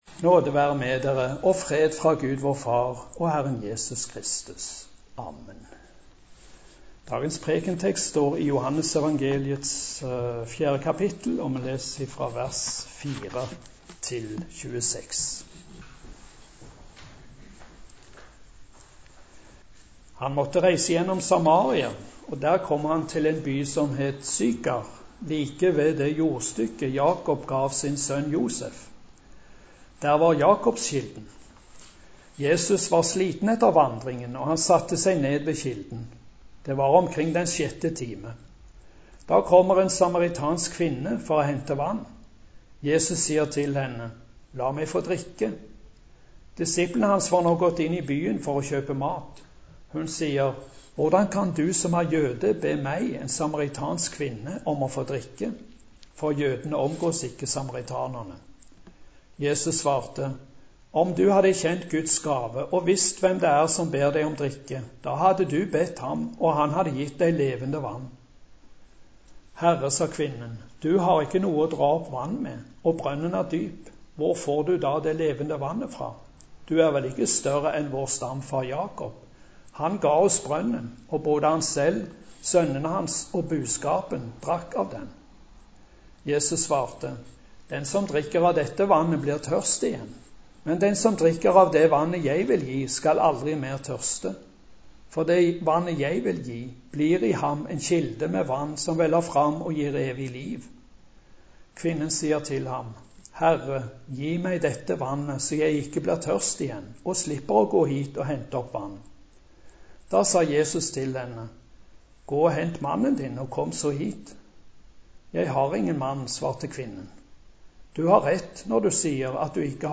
Preken på 2. søndag etter Kristi åpenbaringsdag